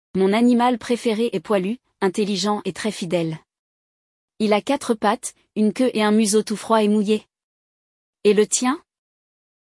No episódio desta semana, nós ouvimos um diálogo entre duas pessoas que não se conhecem, mas acabam conversando casualmente em um parque sobre suas preferências quando o assunto são animais de estimação.